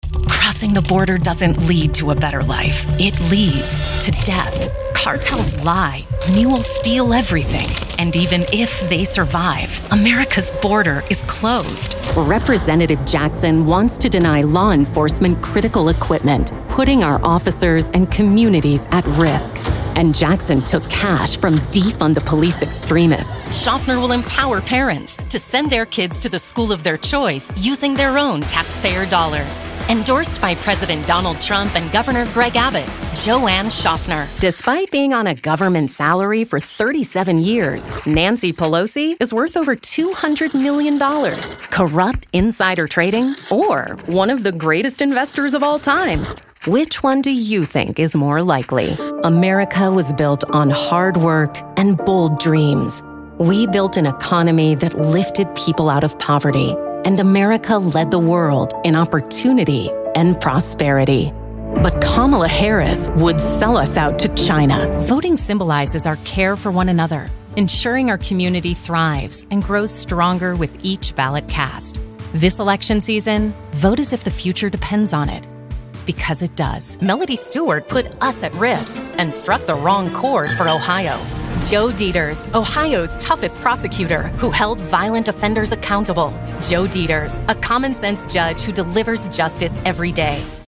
Female Voice Over, Dan Wachs Talent Agency.
Female Republican Voices
Variety of great voice actors with pro home studios and Source Connect.